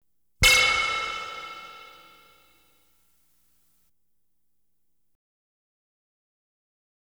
Light Beam Hit Sound Effect
Download a high-quality light beam hit sound effect.
light-beam-hit-7.wav